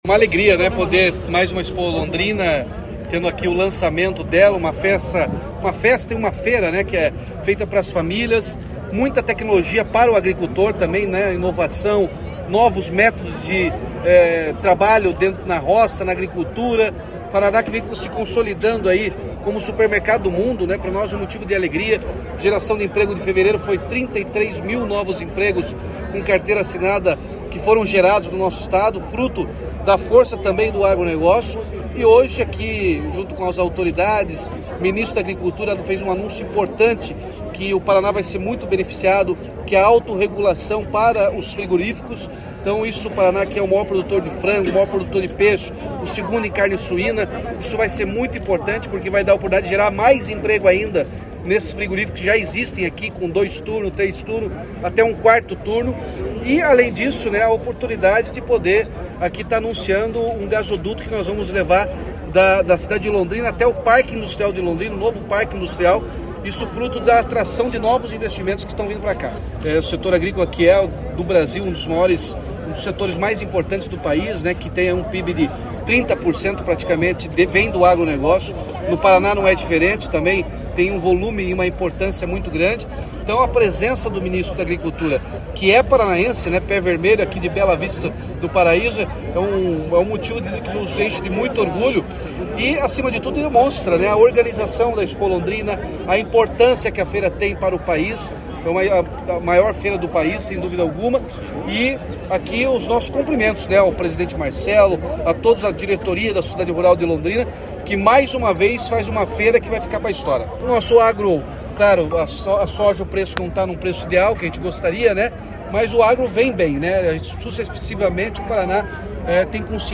Sonora do governador Ratinho Junior na abertura da ExpoLondrina 2024